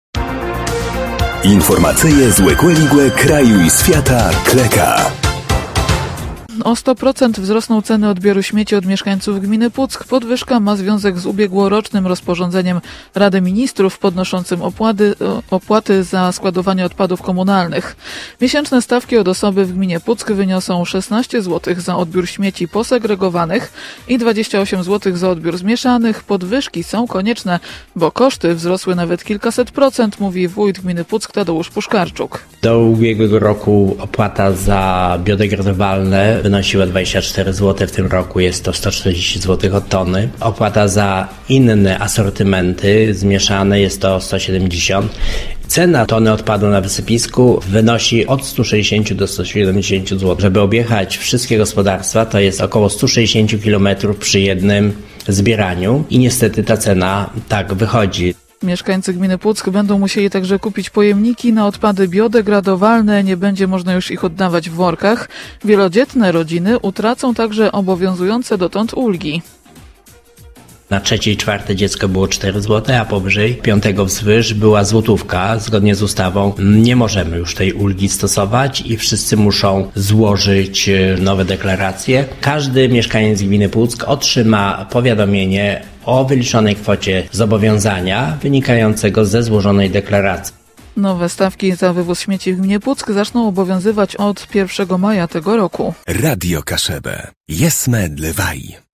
– Podwyżki są konieczne bo koszty wzrosły nawet kilkaset procent – mówi wójt gminy Puck, Tadeusz Puszkarczuk.